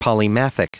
Prononciation du mot polymathematicianic en anglais (fichier audio)
Prononciation du mot : polymathematicianic